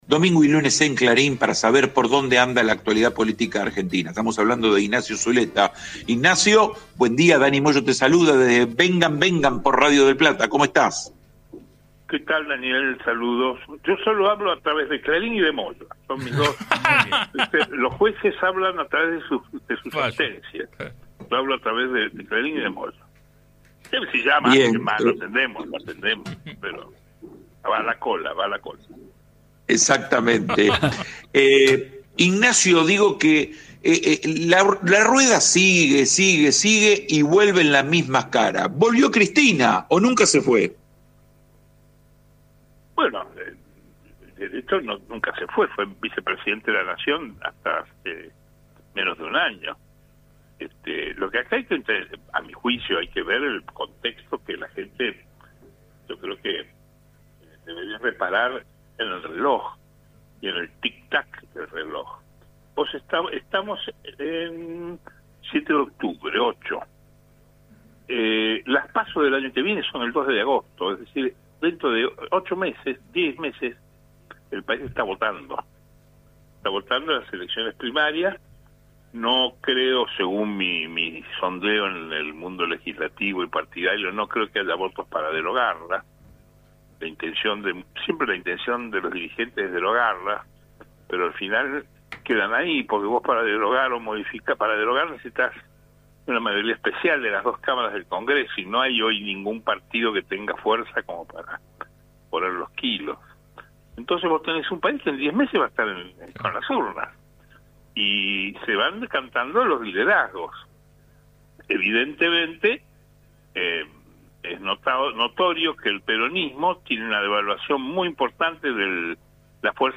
UNIVERSIDADES: EL PRO SE SIGUE COMIENDO A MILEI (Un diálogo por radio)